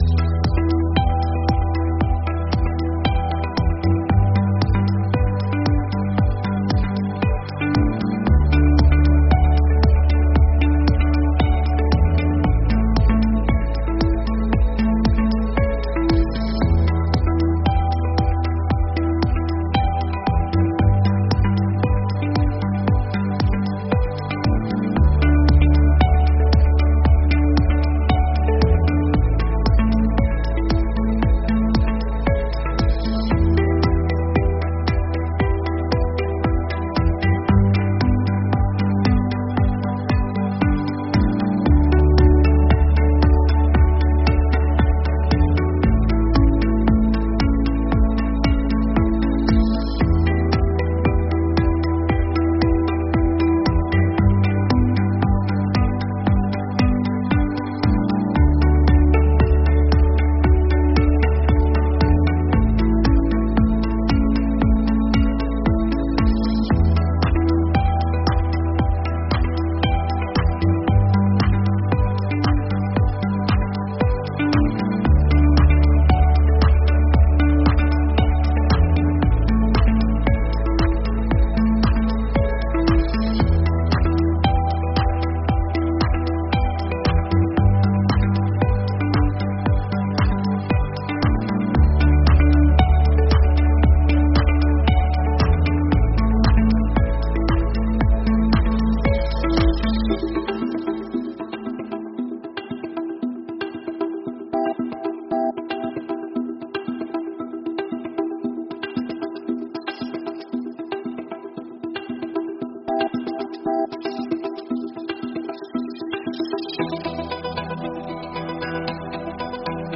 Música d’entrada de trucada
PD: Estan molt comprimides perquè les pugueu escoltar.